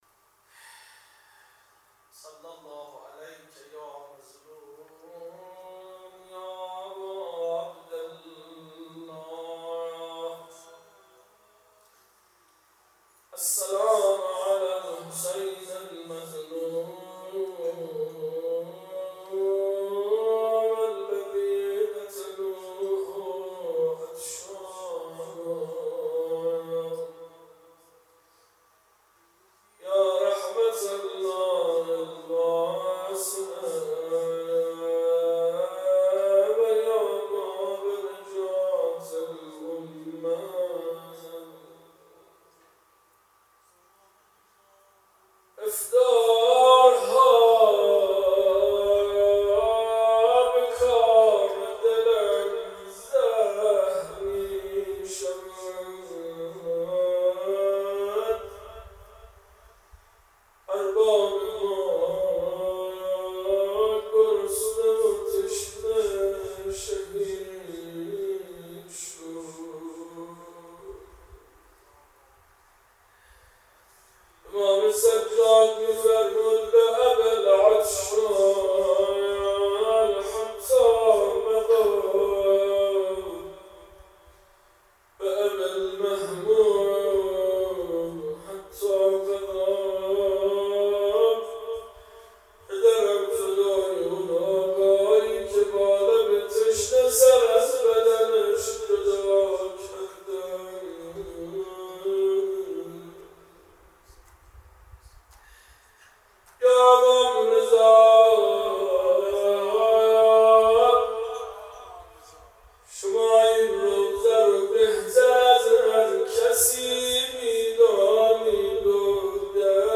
روضه شبهای رمضان ، روز دوم ،۱۳۹۶/۳/۷